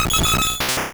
Cri de Dodrio dans Pokémon Rouge et Bleu.